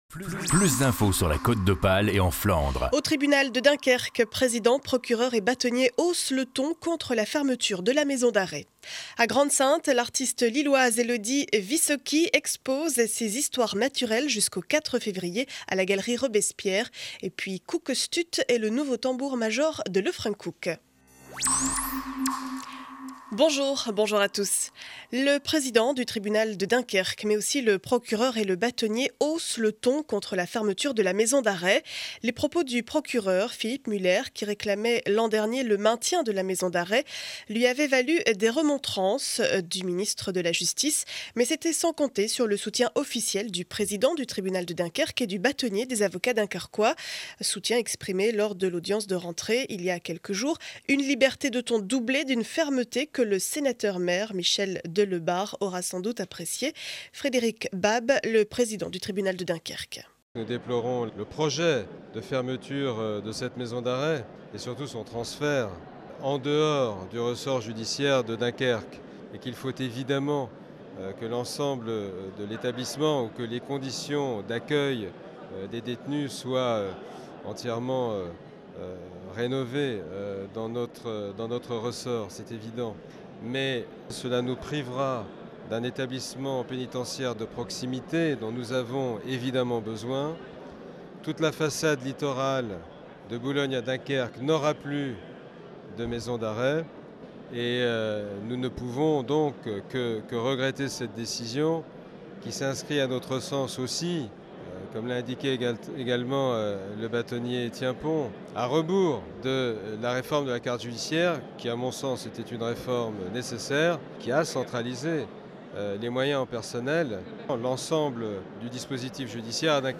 Journal du lundi 16 janvier 2012 7 heures 30 édition du Dunkerquois.